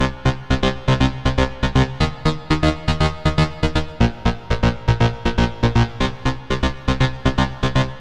loops basses dance 120 - 2
Téléchargez et écoutez tous les sons et loops de basses style dance music tempo 120bpm enregistrés et disponibles sur les banques de sons gratuites en ligne d'Universal-Soundbank pour tous les musiciens, cinéastes, studios d'enregistrements, DJs, réalisateurs, soundesigners et tous ceux recherchant des sons de qualité professionnelle.